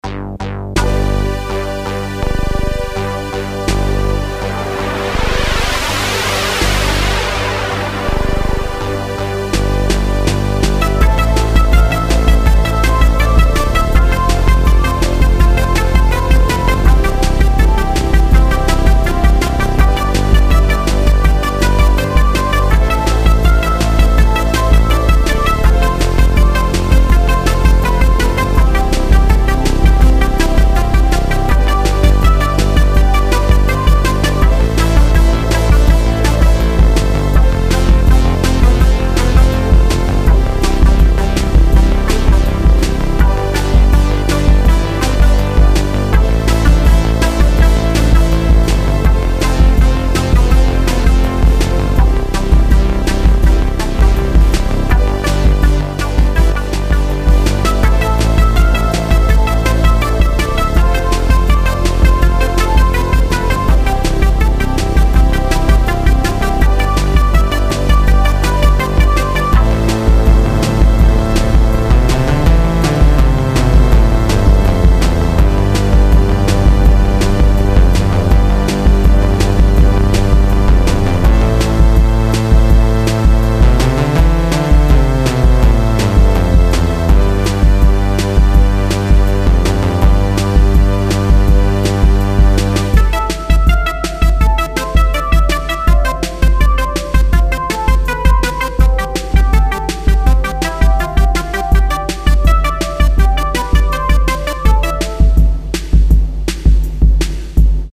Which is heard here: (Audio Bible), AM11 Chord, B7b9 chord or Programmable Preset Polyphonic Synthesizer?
Programmable Preset Polyphonic Synthesizer